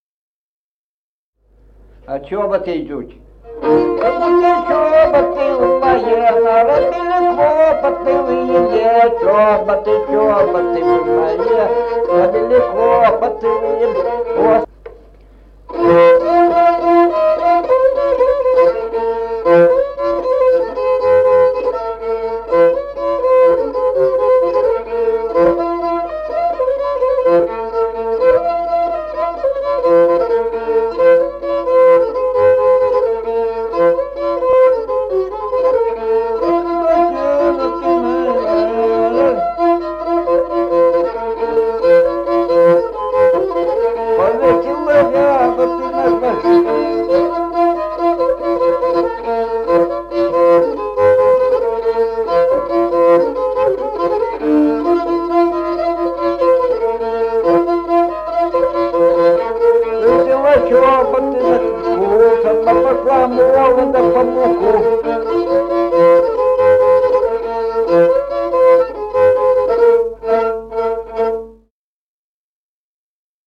Музыкальный фольклор села Мишковка «Чоботы», репертуар скрипача.